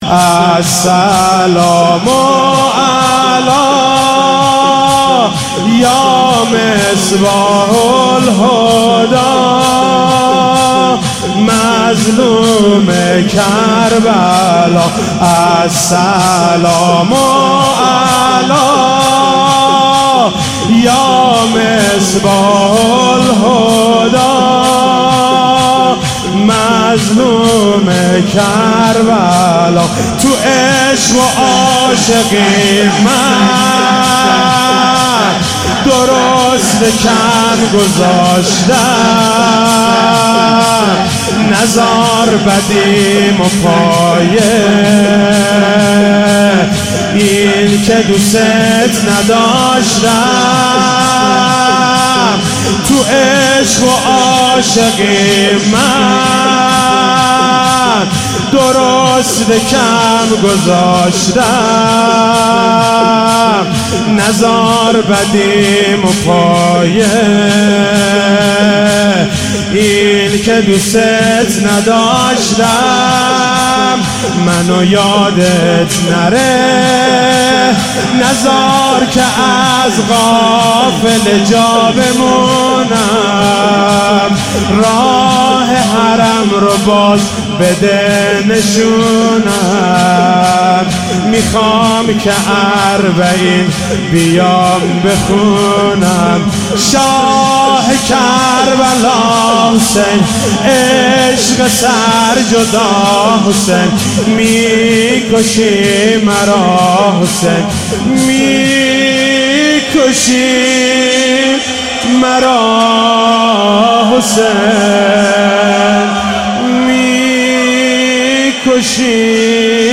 محرم 95
شور